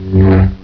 1 channel
saberswg.wav